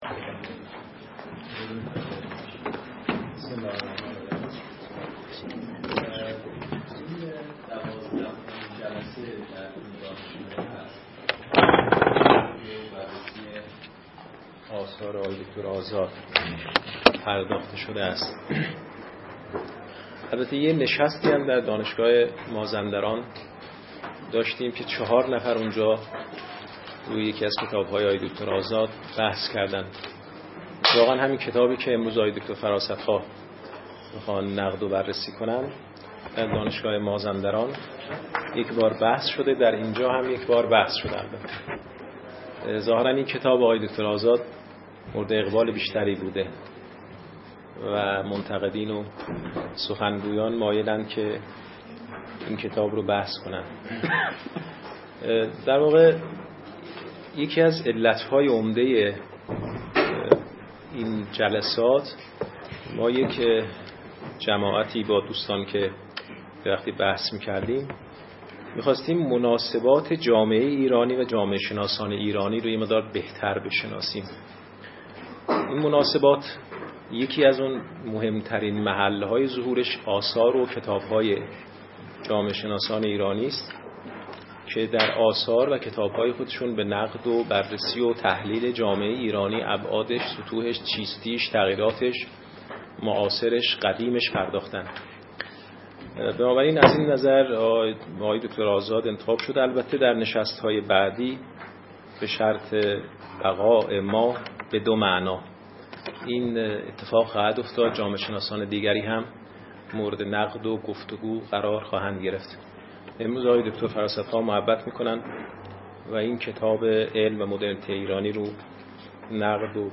این نشست به همت انجمن جامعهشناسی ایران دی ماه ۹۳ در این مرکز برگزار شد.